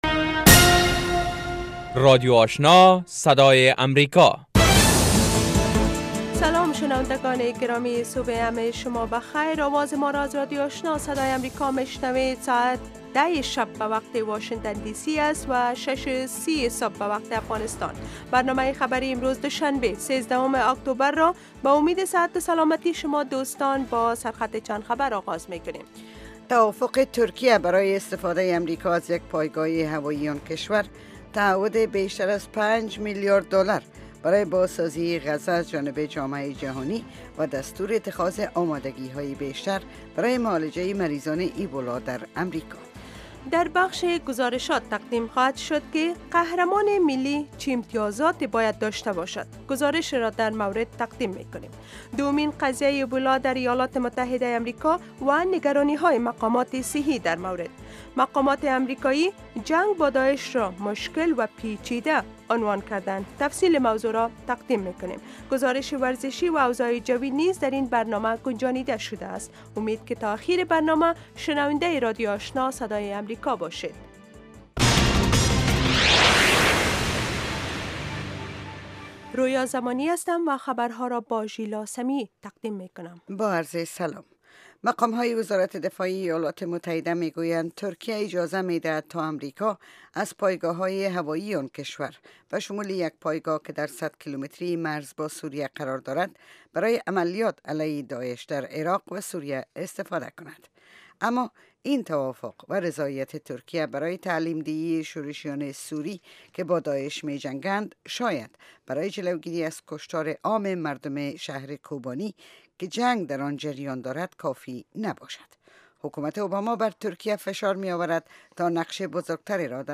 morning news show second part